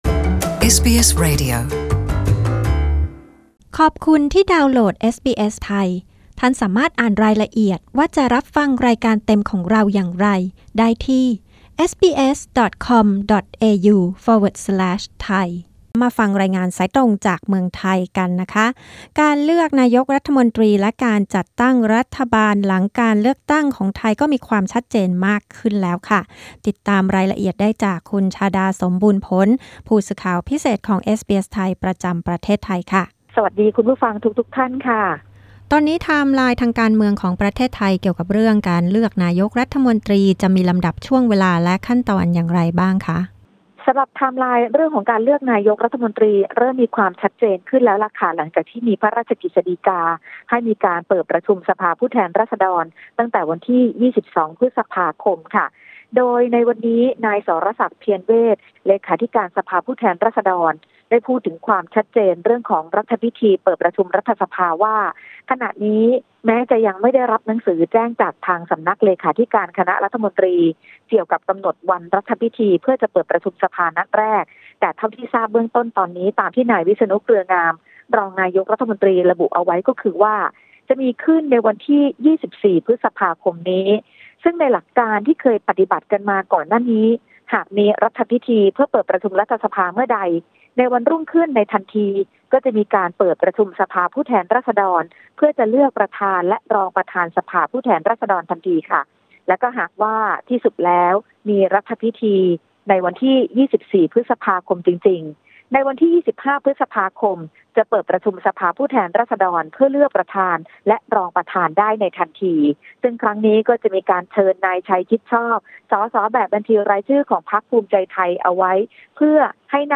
รายงานนี้ออกอากาศเมื่อคืนวันพฤหัสบดี ที่ 16 พ.ค. ในรายการวิทยุเอสบีเอส ไทย เวลา 22.00 น.